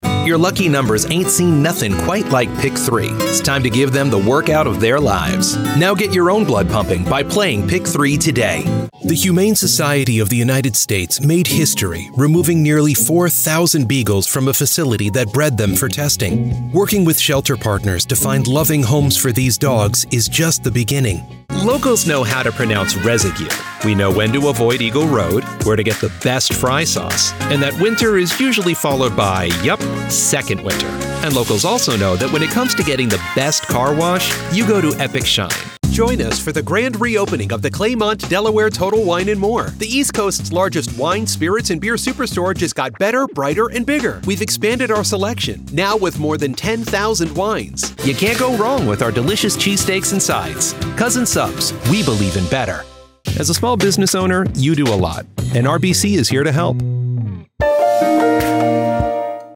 Engels (Amerikaans)
Commercieel, Speels, Vertrouwd, Vriendelijk, Warm
Commercieel